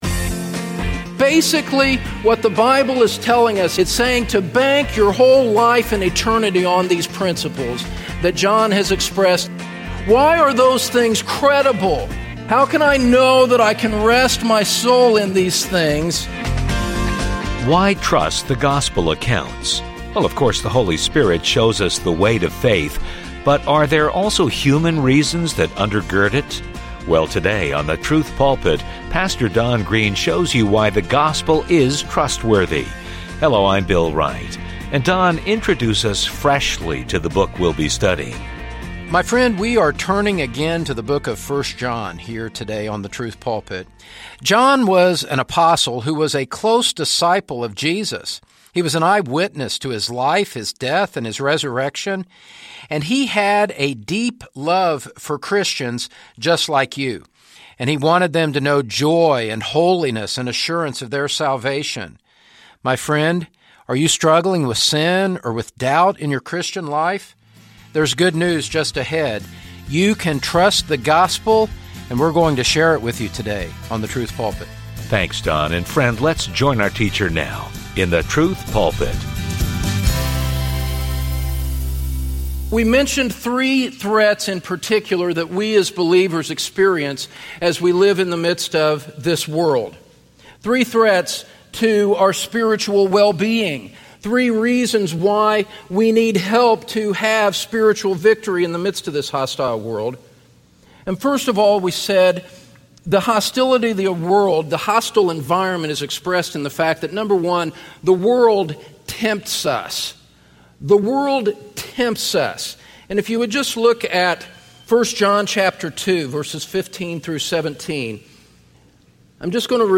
The Gospel is Trustworthy, #1 | SermonAudio Broadcaster is Live View the Live Stream Share this sermon Disabled by adblocker Copy URL Copied!